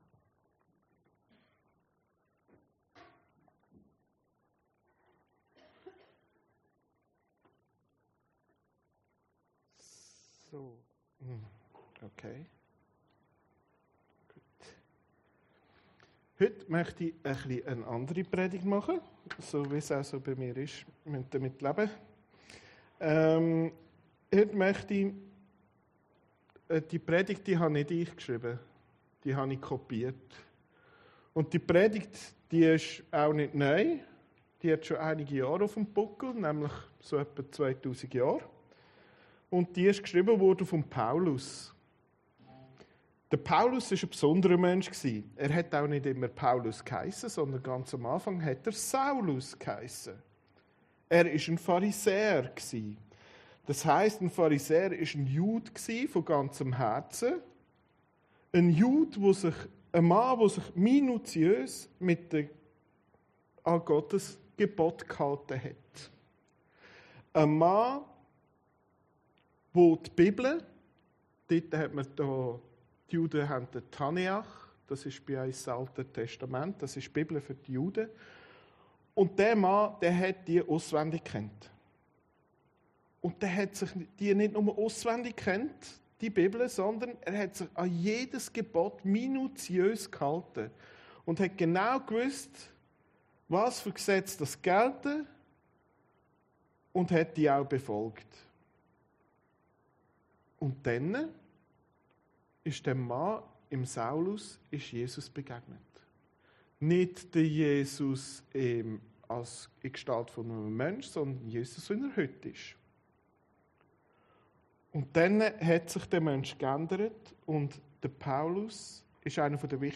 Predigten Heilsarmee Aargau Süd – Die Story von Sünde und Gnade